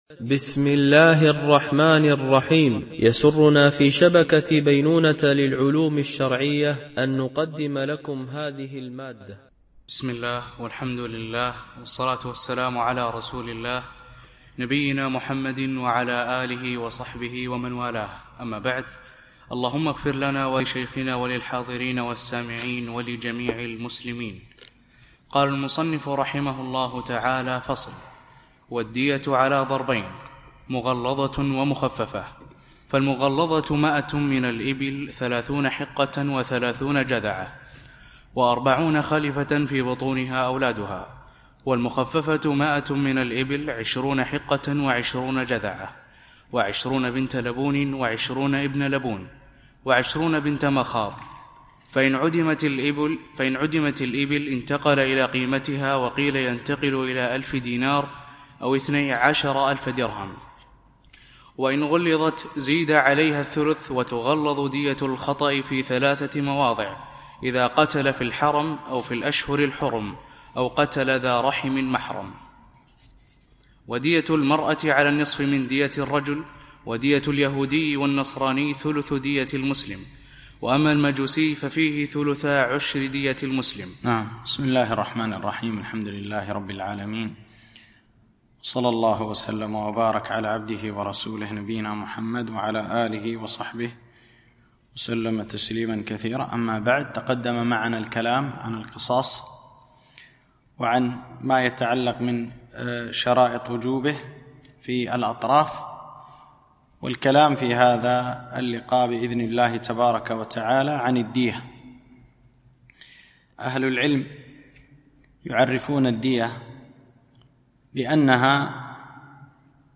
شرح متن أبي شجاع في الفقه الشافعي ـ الدرس 38